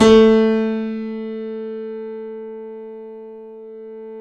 Index of /90_sSampleCDs/Roland - Rhythm Section/KEY_YC7 Piano ff/KEY_ff YC7 Mono